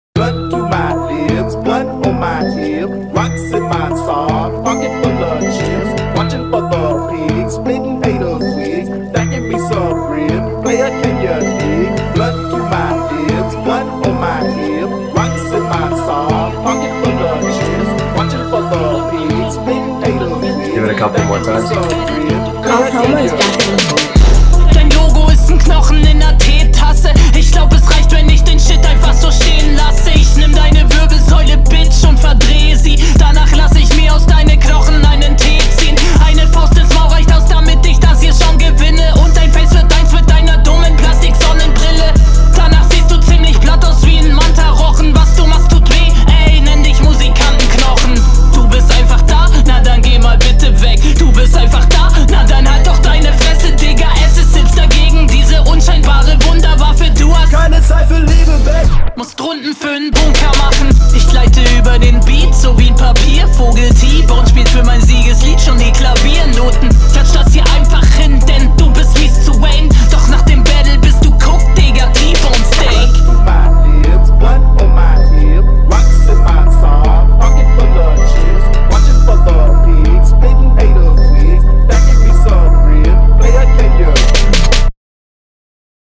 Unfassbar nice geflowt.